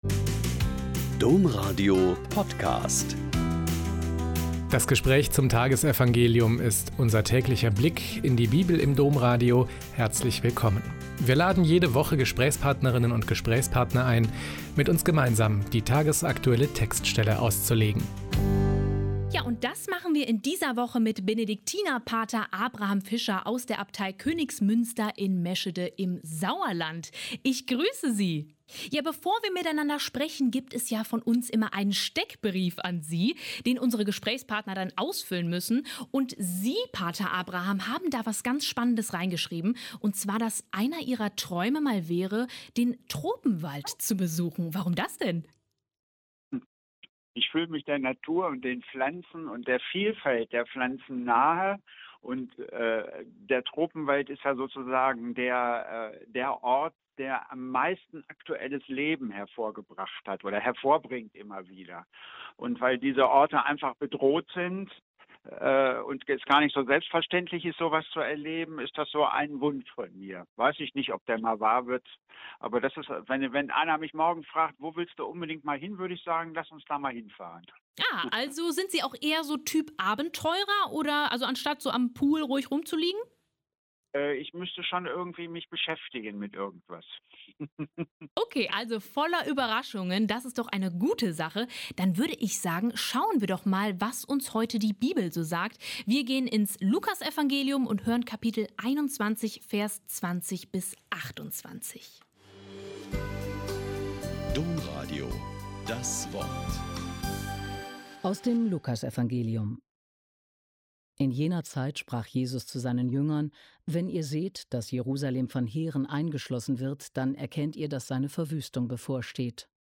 Lk 21,20-28 - Gespräch